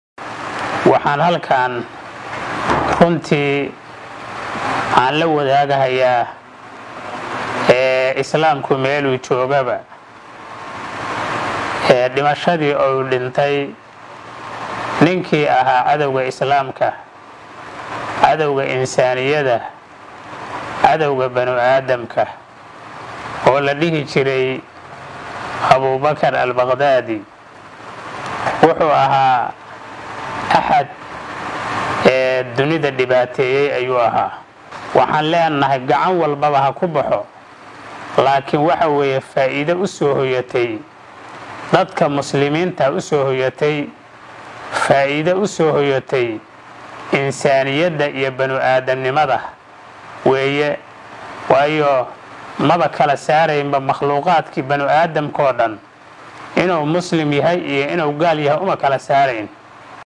Dhuusamareeb (Caasimada Online)  – Madaxa xukuumadda Galmudug, kana mid ah hoggaanka Ahlu Sunna Sheekh Maxamed Shakaarir Cali Xasan oo shir jaraa’id ku qabtay Dhuusamareeb ayaa ka hadlay geerida hoggaamiyaha Ururka Daacish oo uu dhowaan sheegay Mareykanka inuu ku dilay weerar qorsheysnaa oo ka dhacay dalka Syria.